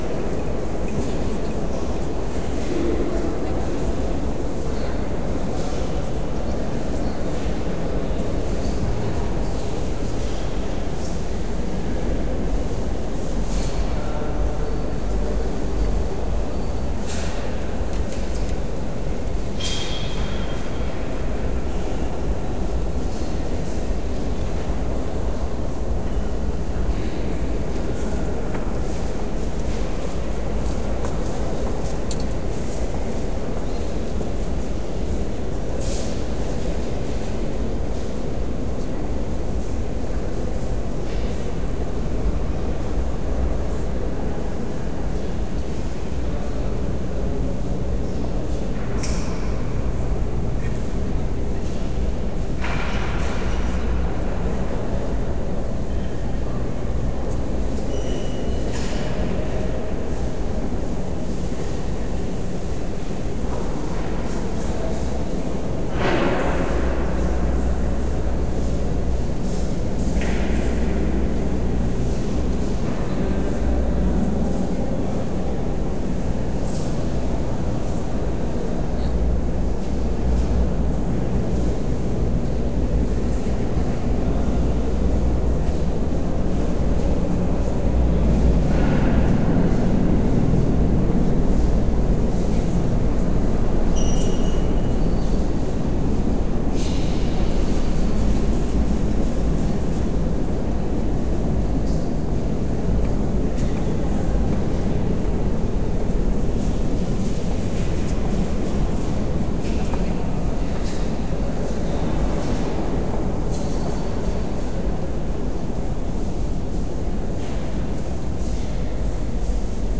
garage.ogg